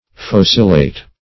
Search Result for " focillate" : The Collaborative International Dictionary of English v.0.48: Focillate \Foc"il*late\, v. t. [L. focilatus, p. p. of focillare.]
focillate.mp3